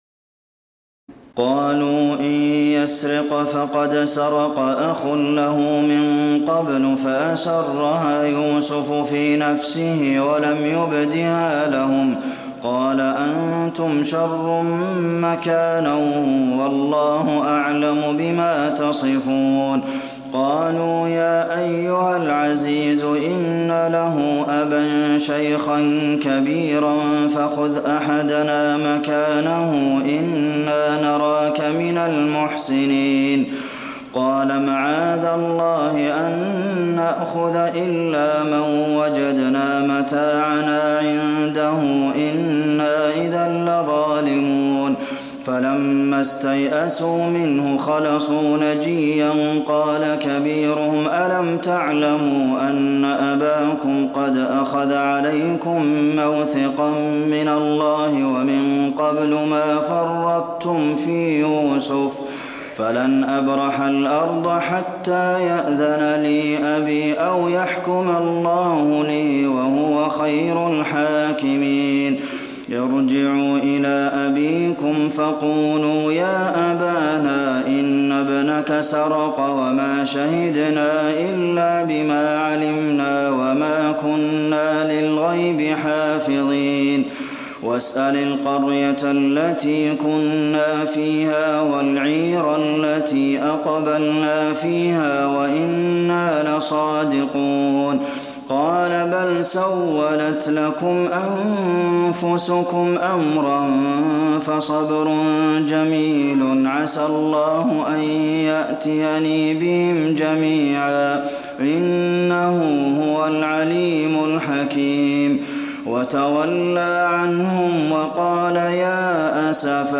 تراويح الليلة الثانية عشر رمضان 1426هـ من سورتي يوسف (77-111) و الرعد (1-32) Taraweeh 12 st night Ramadan 1426H from Surah Yusuf and Ar-Ra'd > تراويح الحرم النبوي عام 1426 🕌 > التراويح - تلاوات الحرمين